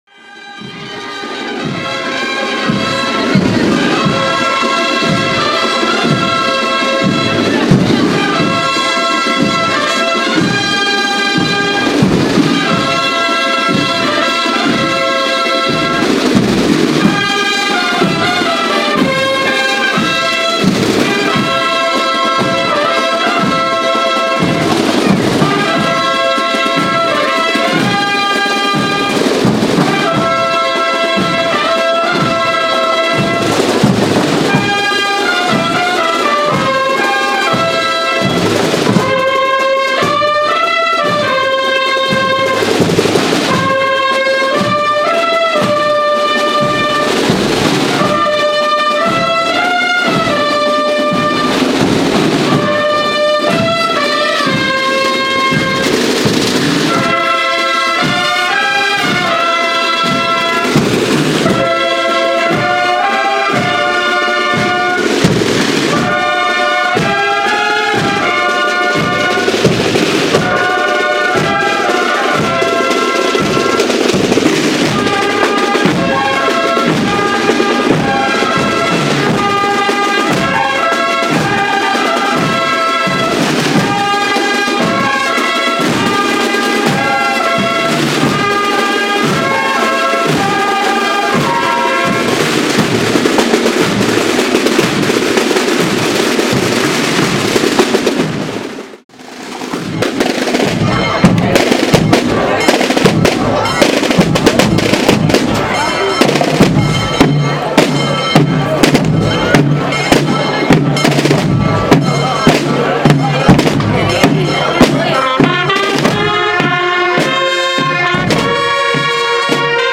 Procesión San Roque 2014
Posteriormente tuvo lugar la procesión con la imagen de San Roque que recorrió las calles del barrio, para finalizar en la Ermita y que contó con la asistencia de vecinos, autoridades y la banda de cornetas y tambores de la Hermandad de Jesús en el Calvario y Santa Cena.